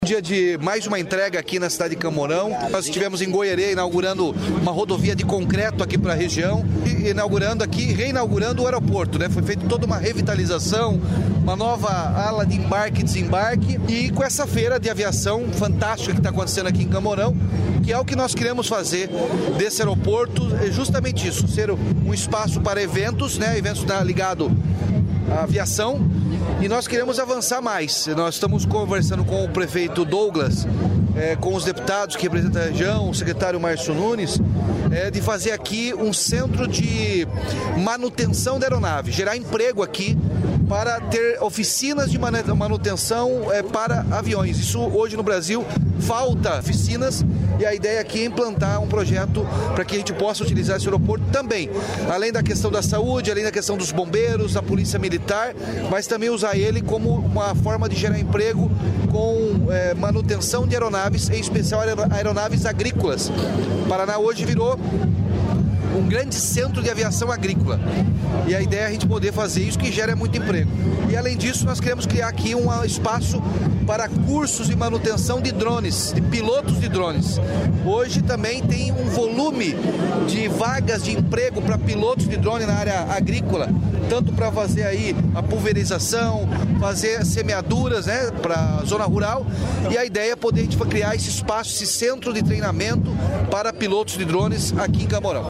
Sonora do governador Ratinho Junior sobre nova pista do aeroporto de Campo Mourão